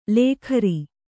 ♪ lēkhari